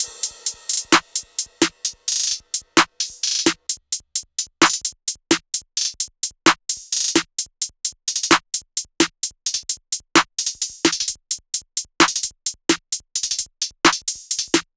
SOUTHSIDE_beat_loop_banger_top_130.wav